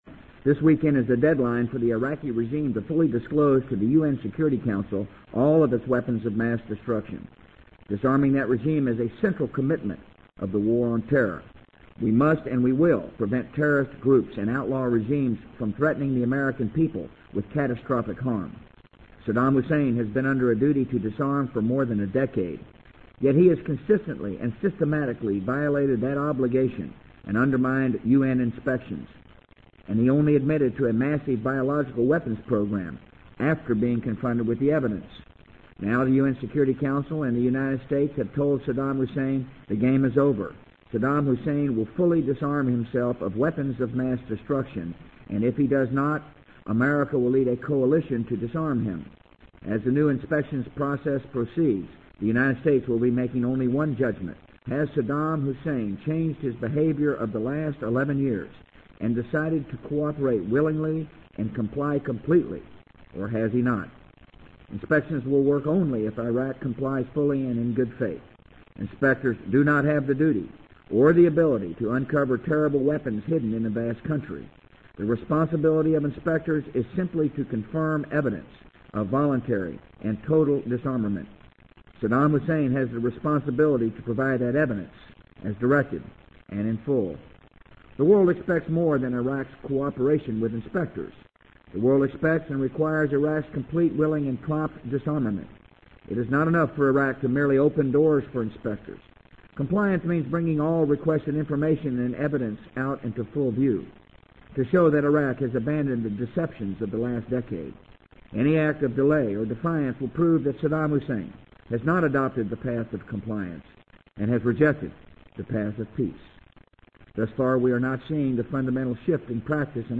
【美国总统George W. Bush电台演讲】2002-12-07 听力文件下载—在线英语听力室